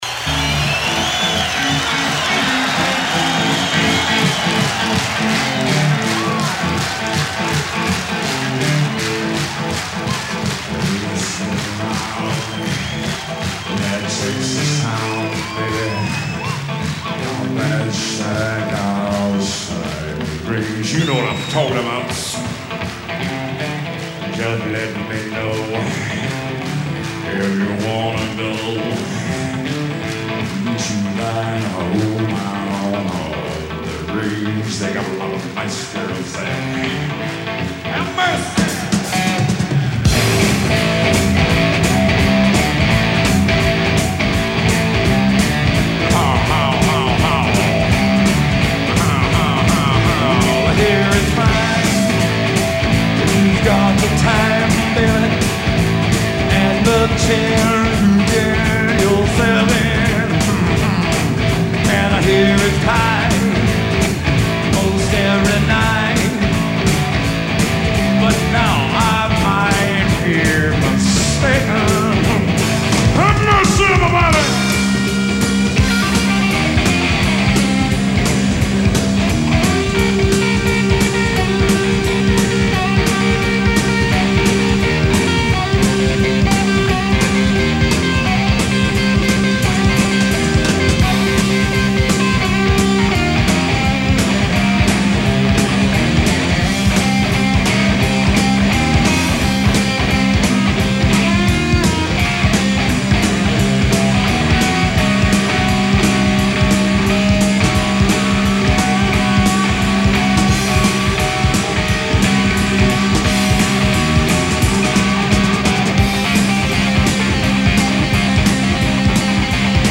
a live medley
Just for slipping that cassette soundboard recording to me